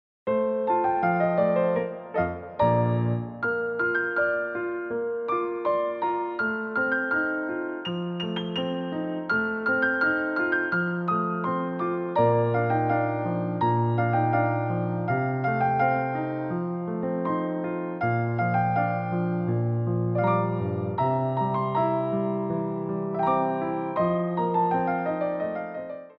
Theme Songs from Musicals for Ballet Class
Piano Arrangements
Degagés
4/4 (16x8)